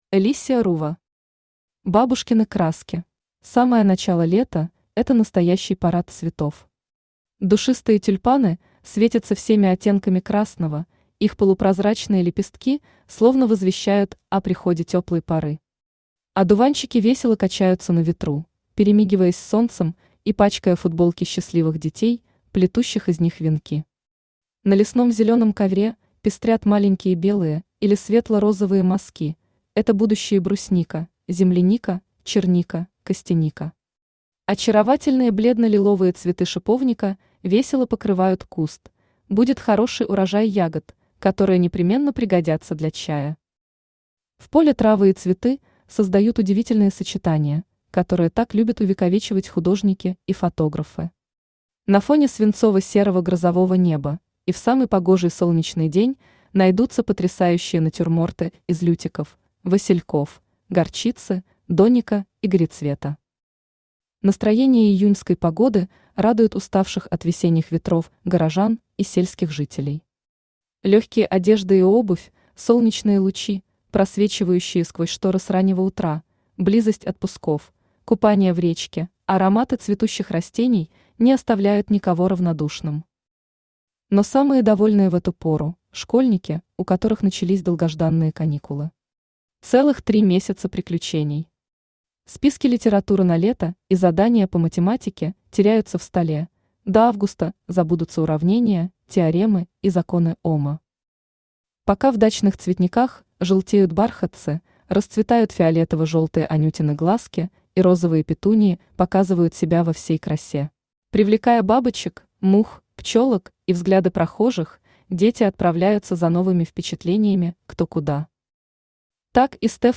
Аудиокнига Бабушкины краски | Библиотека аудиокниг
Aудиокнига Бабушкины краски Автор Alicia Ruva Читает аудиокнигу Авточтец ЛитРес.